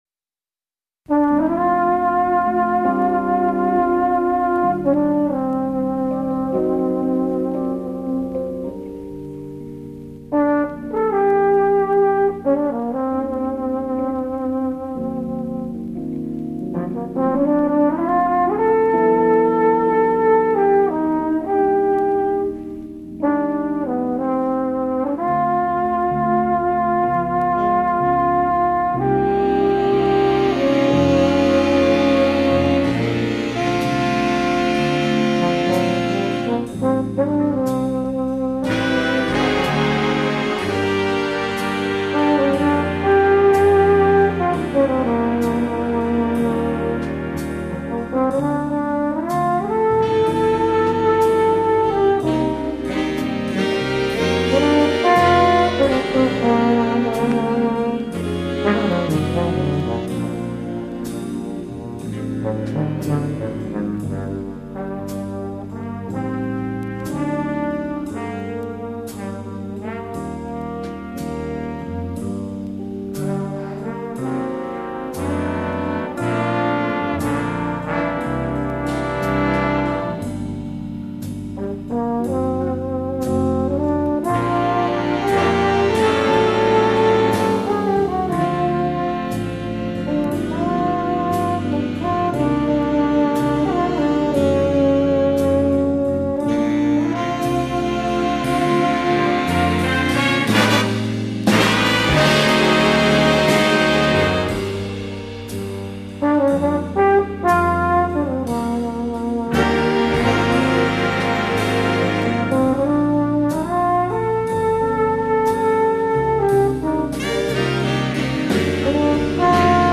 jazz
euphonium